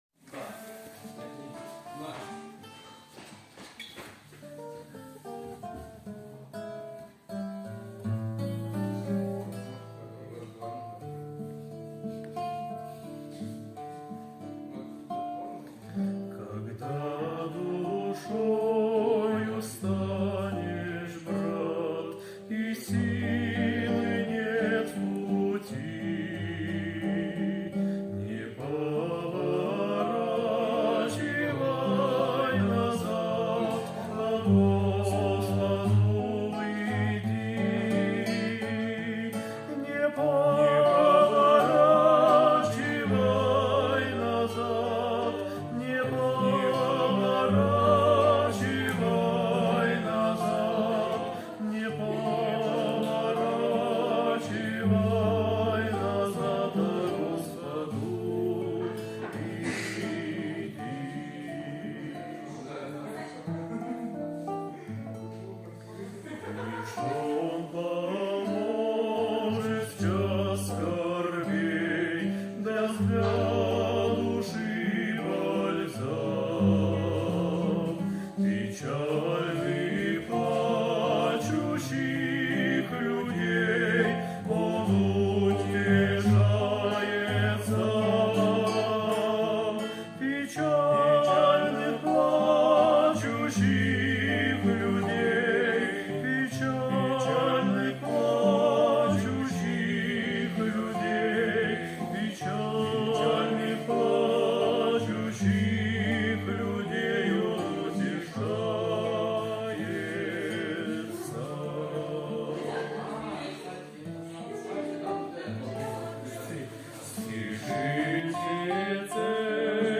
133 просмотра 138 прослушиваний 1 скачиваний BPM: 191